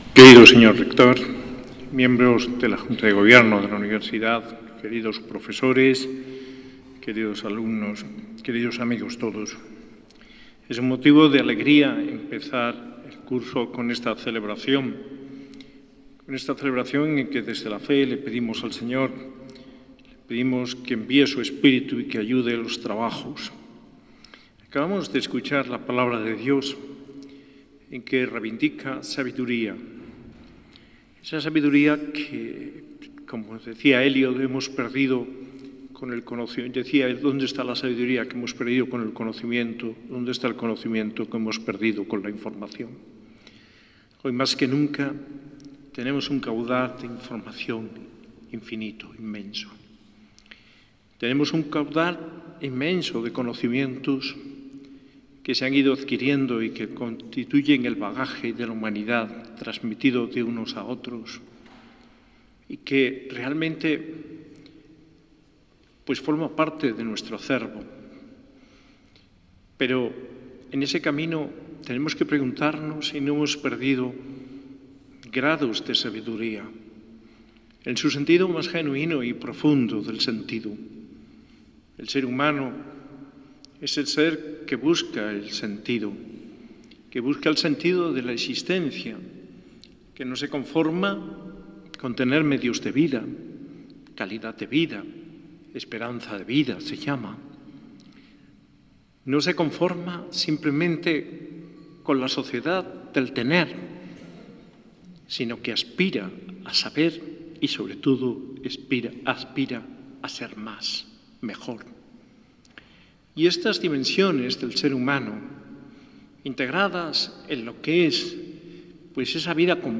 Homilía de Mons. José María Gil Tamayo, arzobispo de Granada, en la Eucaristía de inicio de curso con la Universidad de Granada el 12 de septiembre de 2025, en la parroquia de Santos Justo y Pastor.